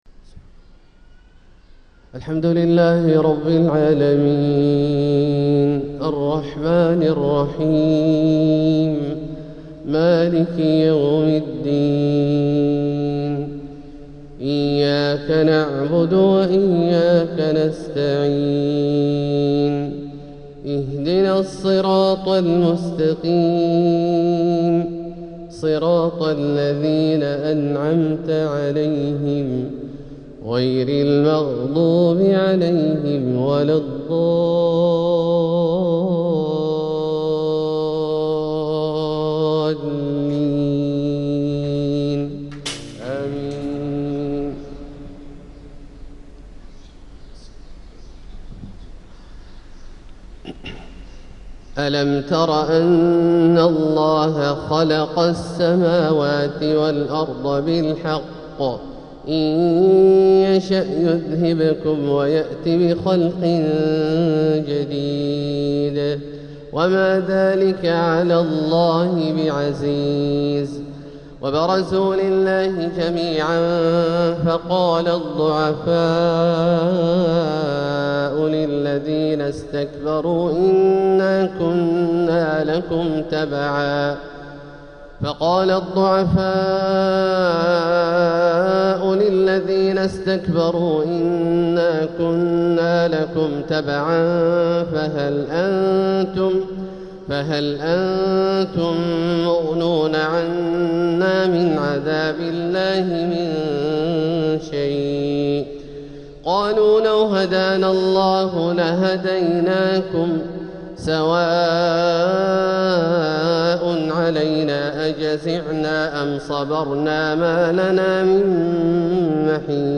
تلاوة من سورة إبراهيم 19-41 | فجر الإثنين 3-6-1447هـ > ١٤٤٧هـ > الفروض - تلاوات عبدالله الجهني